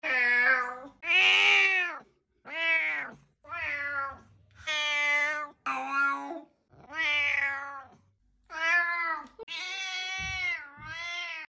Just a normal day full of meows